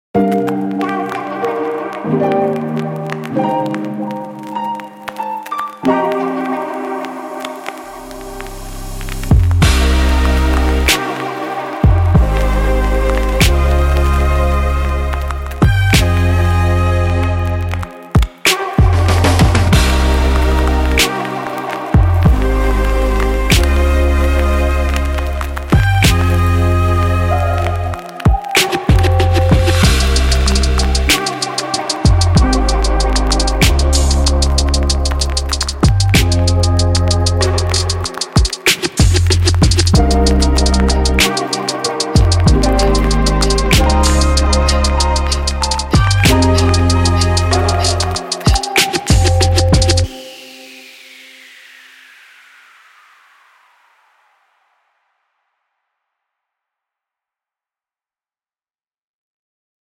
3. Trap
借助这些多汁的鼓声循环，悠闲的合成器模式，模糊的低音循环以及更多其他内容，深入陷阱的陷阱！
酥脆的鼓声，深达808的打击以及详细的FX，可帮助增加音轨的张力。